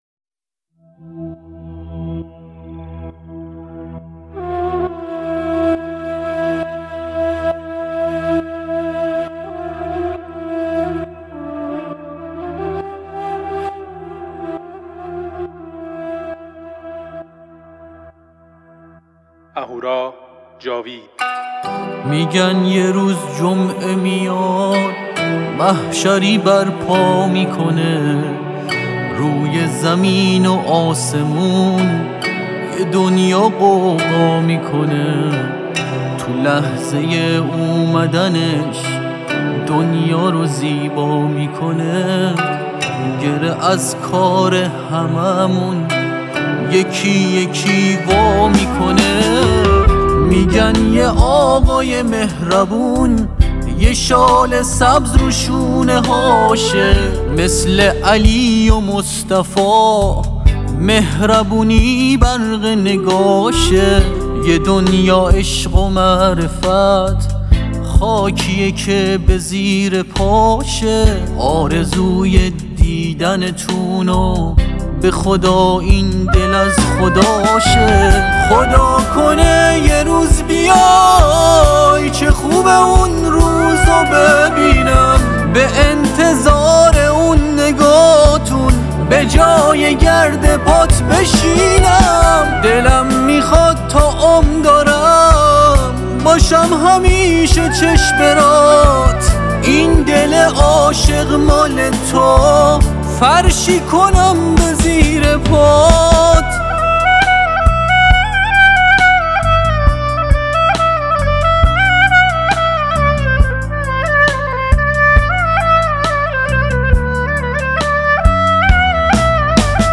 مذهبی و نوحه